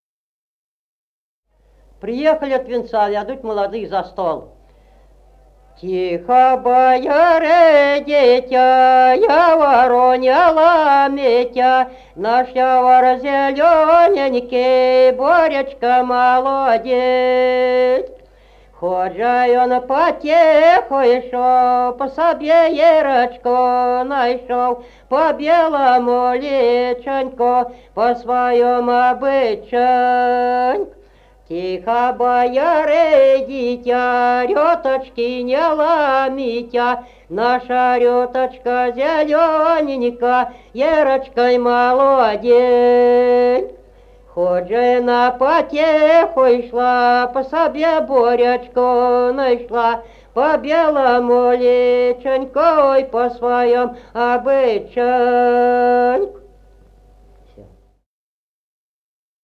1953 г., д. Камень.